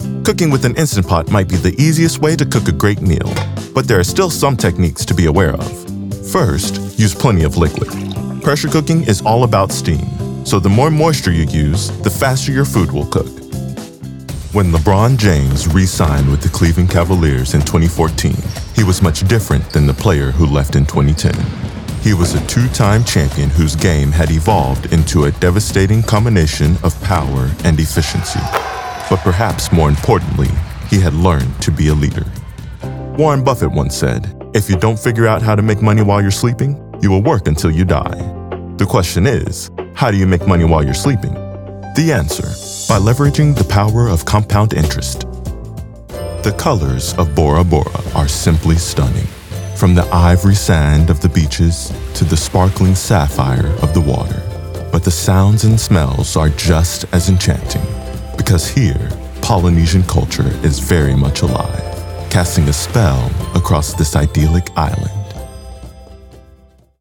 Narration Demos - Authoritative - Dramatic - Strong
Young Adult
Backed by extensive performance coaching and a rigorous daily audition practice, I offer a professional-grade home studio setup for quick turnarounds.